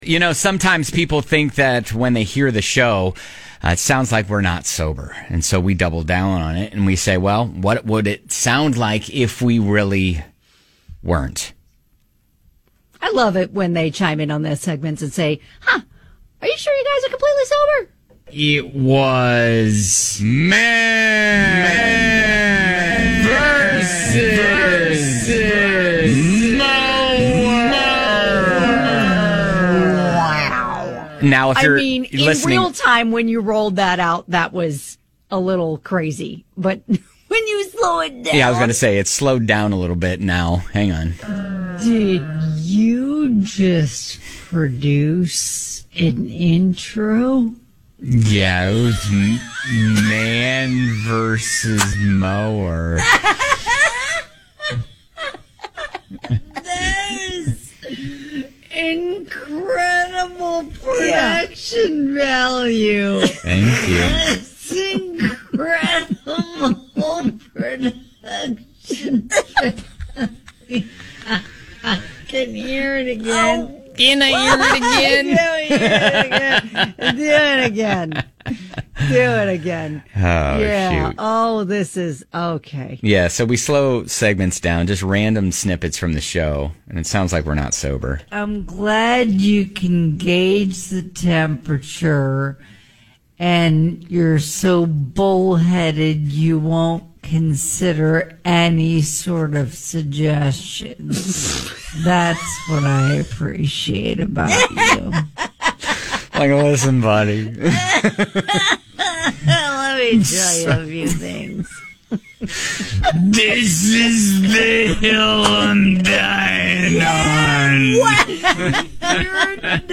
People have wondered what the show would sound like if we did it "not sober"... so we slow down audio of some random conversations we have, and it turns into magic. lol